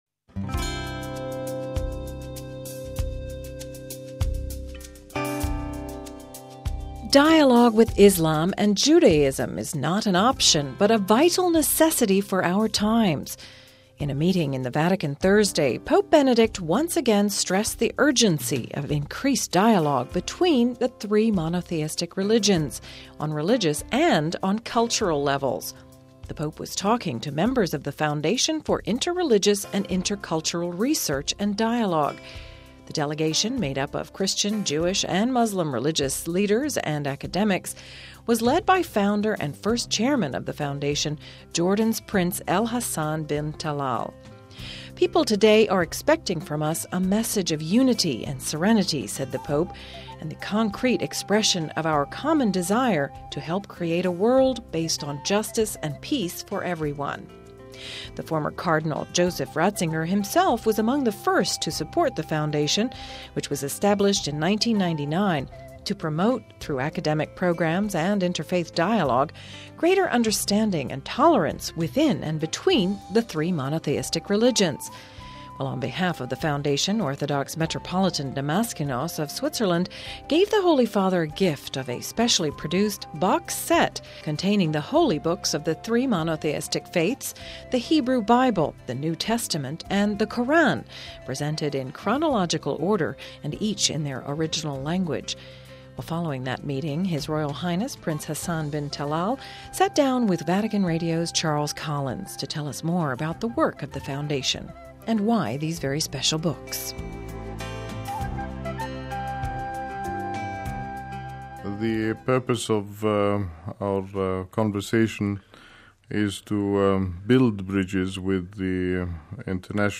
An exclusive interview with Jordan’s Royal Prince El Hassan Bin Talal after a meeting between Pope Benedict and the Foundation for Interreligious and Intercultural Research and Dialogue...